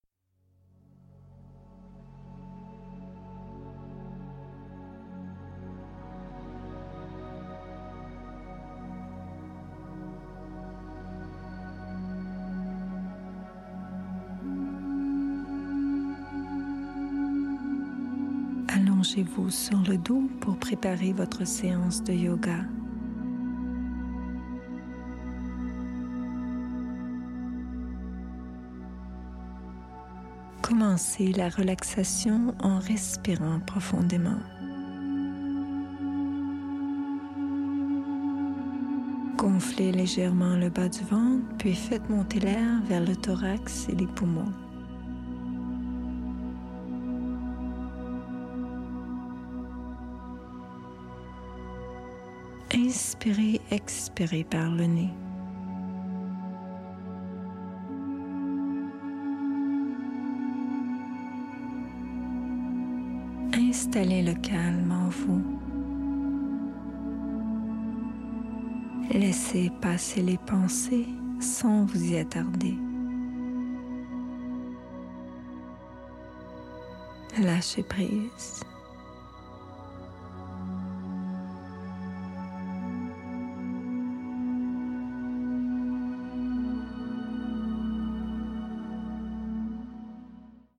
Une session guidée de yoga niveau débutant / intermédiaire avec fond musical comprenant assouplissements, asanas, pranayama, relaxation.